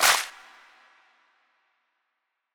TS - CLAP (1).wav